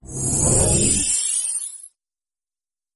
Whoosh Sparkle Sound Effect Free Download
Whoosh Sparkle